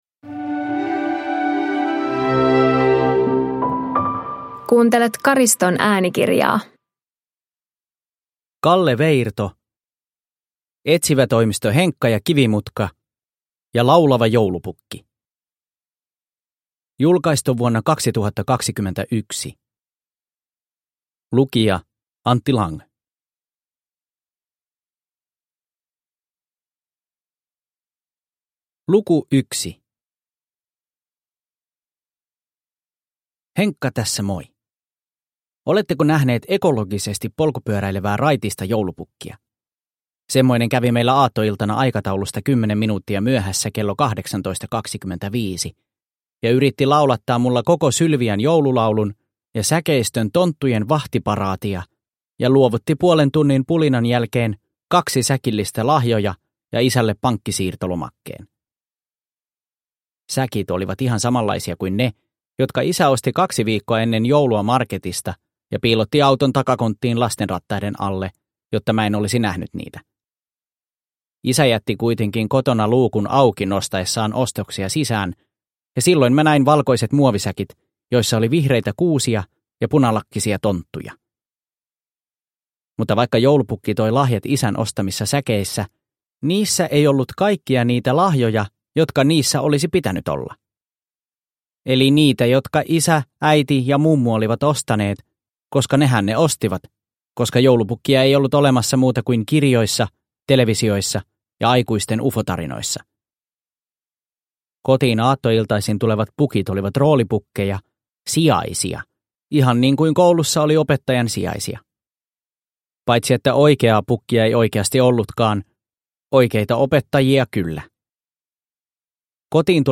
Etsivätoimisto Henkka & Kivimutka ja laulava joulupukki – Ljudbok – Laddas ner